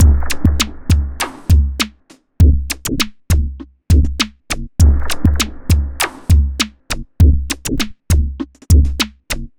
machine 100bpm 02.wav